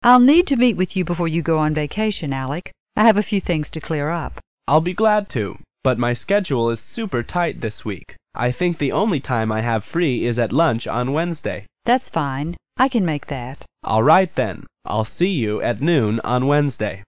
办公室英语会话第46期-Setting up a lunch appointment 约定吃午饭